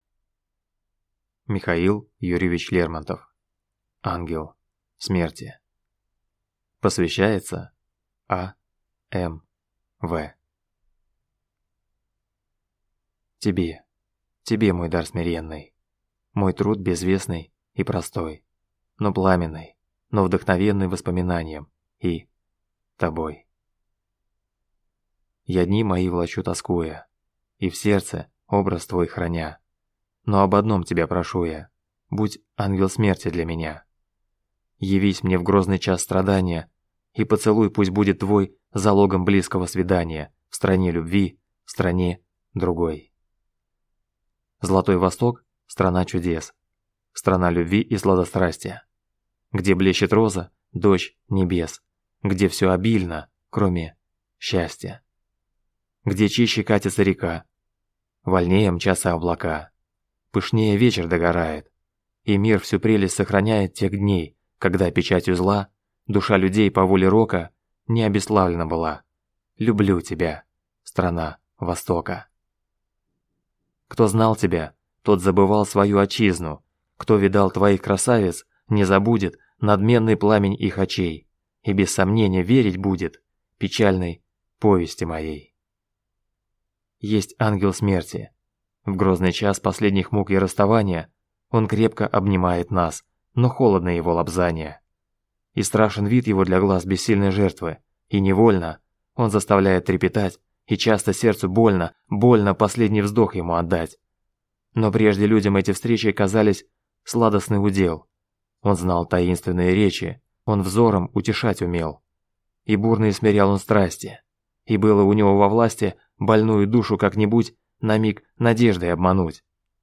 Аудиокнига Ангел смерти | Библиотека аудиокниг